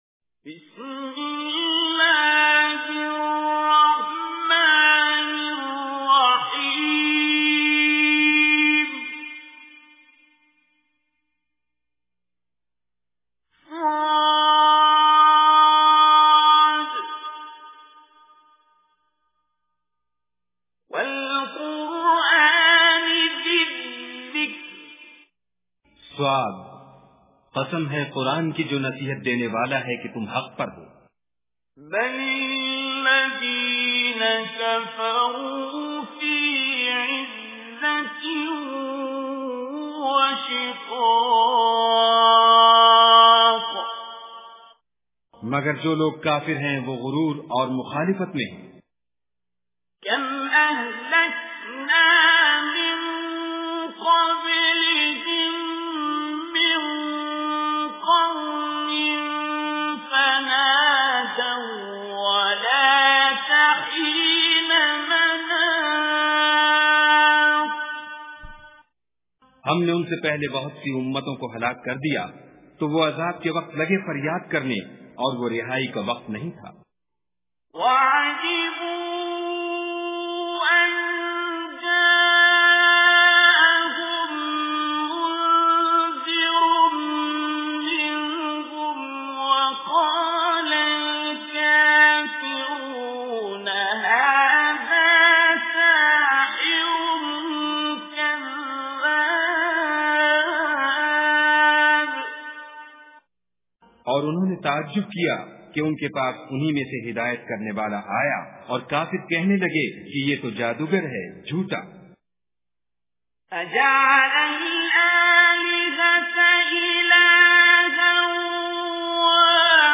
Surah Sad Recitation with Urdu Translation
Surah Sad is 38th chapter of Holy Quran. Listen online and download mp3 tilawat / recitation of Surah Sad in the beautiful voice of Qari Abdul Basit As Samad.